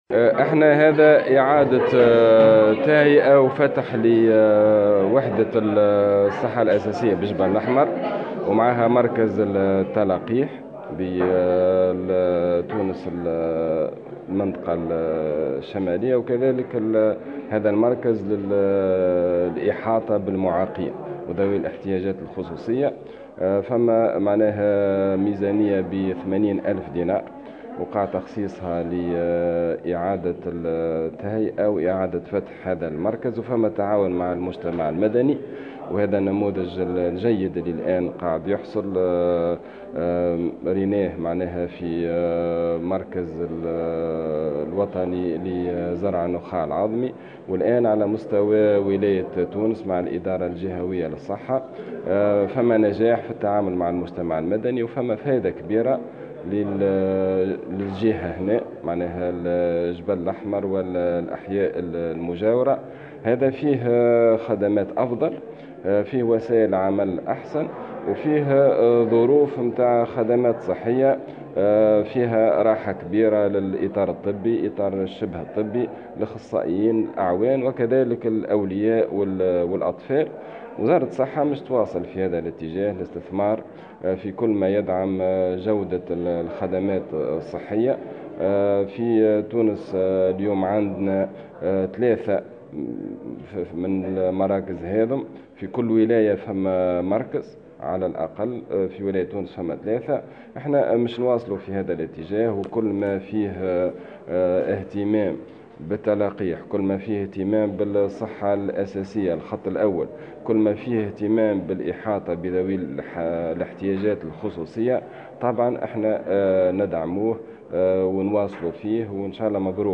Dans une déclaration de presse tenue en marge de l'inauguration d'une unité de santé de base à Djebel Lahmer, le ministre a notamment évoqué le plan du ministère pour la mise en place d'au moins un centre d'aide aux enfants autistes dans chaque gouvernorat.